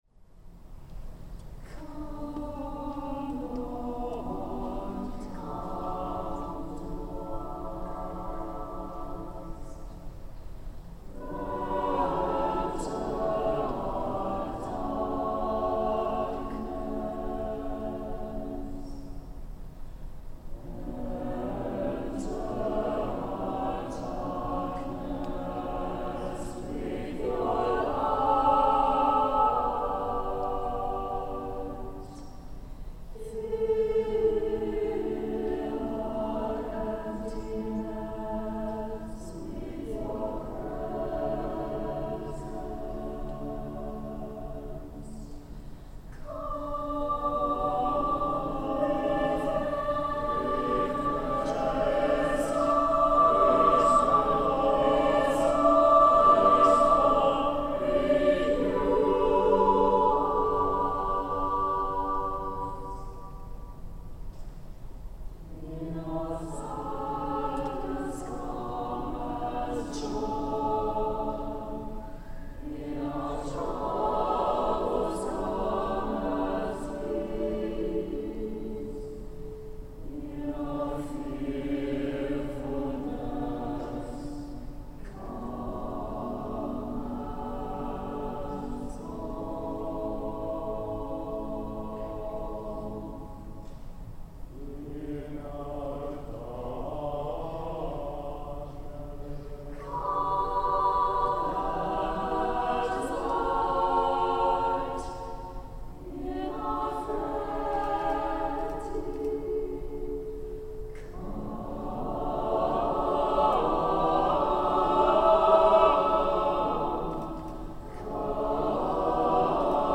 Choral Music
More recently, we were honored to sing for the consecration of our new bishop.